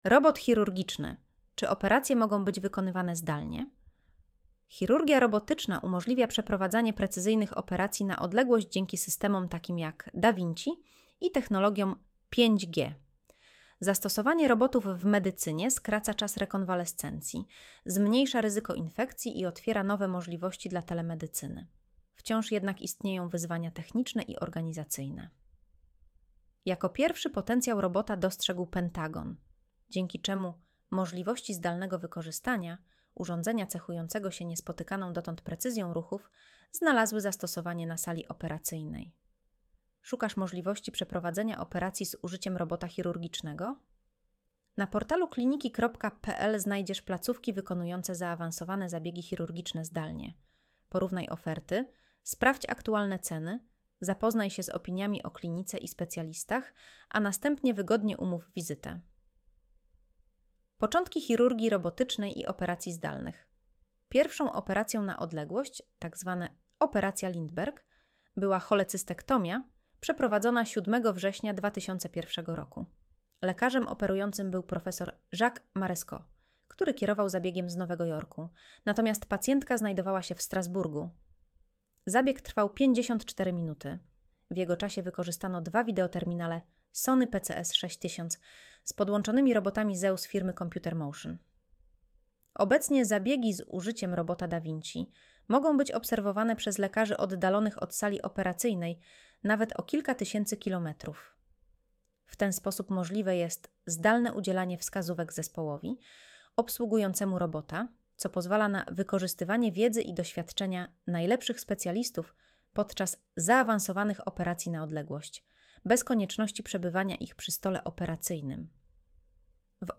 Audio wygenerowane przez AI, może zawierać błędy